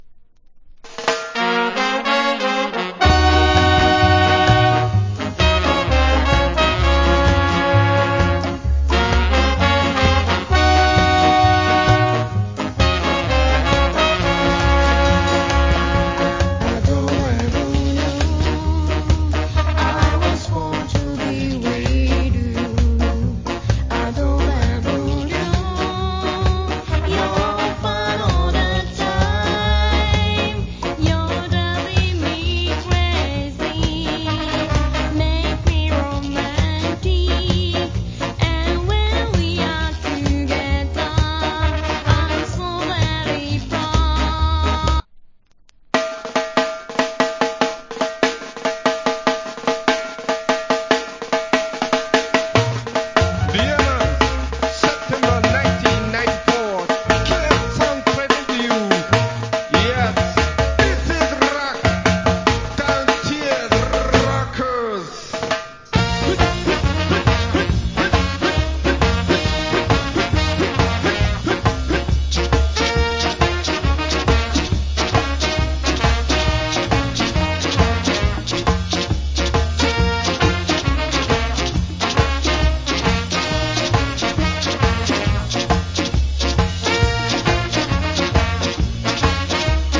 Great Duet Ska Vocal. 1994.